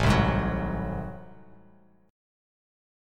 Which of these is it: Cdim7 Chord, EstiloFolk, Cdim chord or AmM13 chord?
AmM13 chord